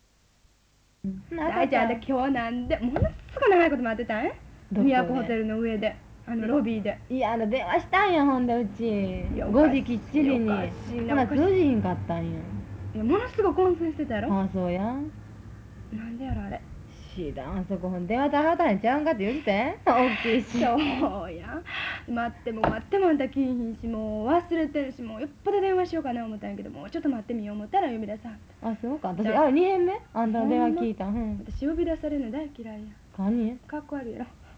• 1964년에 교토 시내에서 기록된 당시 20대 여성 2명의 대화[52]。